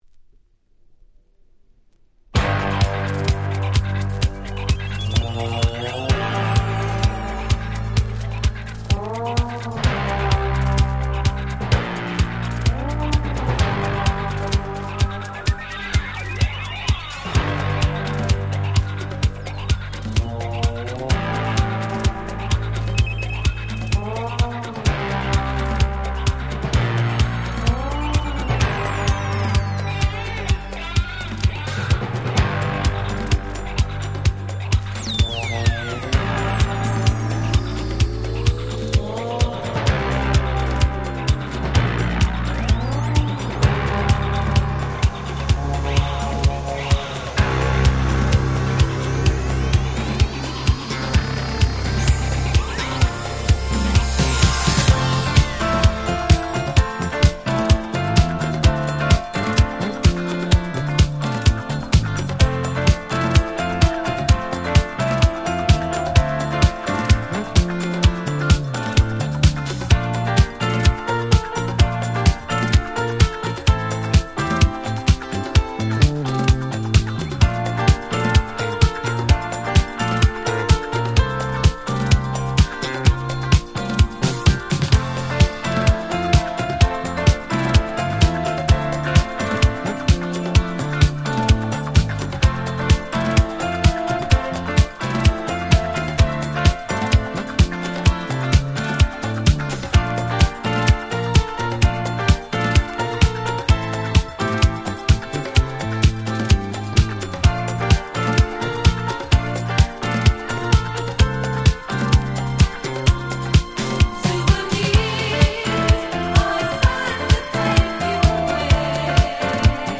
音質もバッチリでめちゃくちゃ使えます！！！